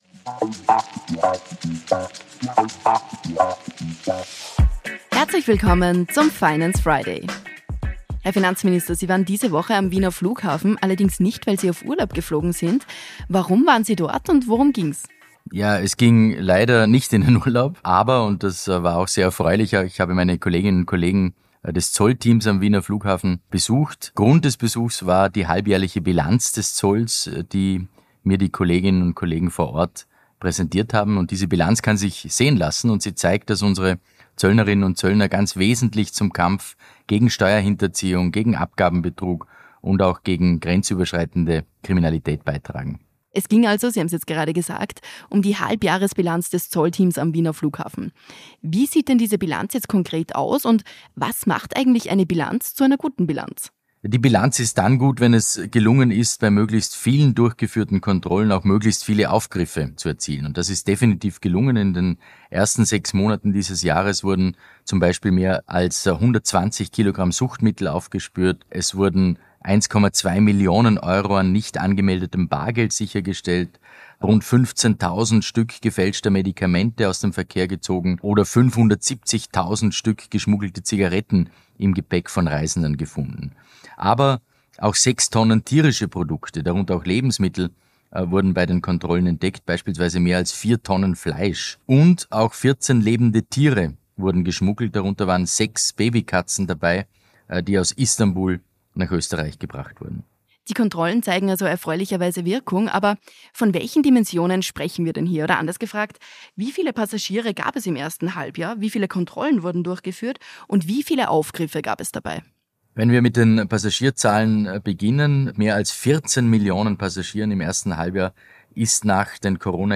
In der aktuellen Folge des „Finance Friday” spricht Finanzminister